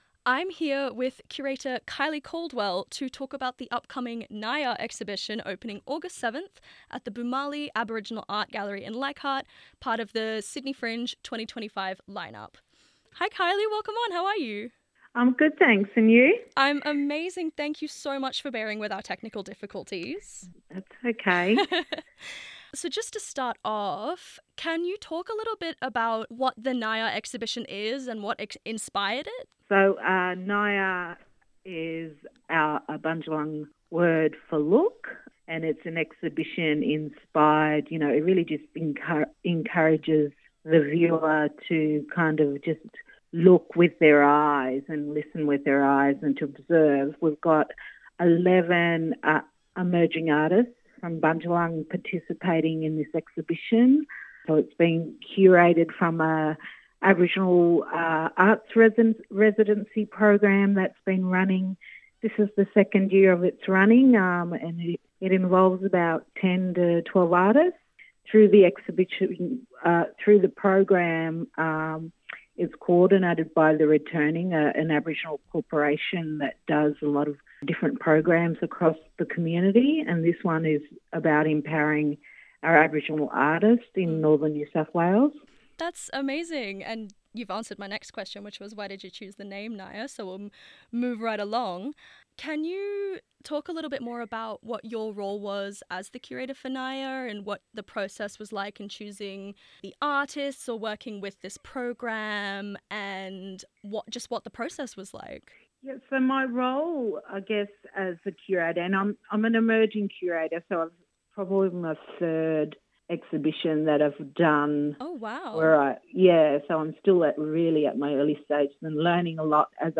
Brendan Cowell Interview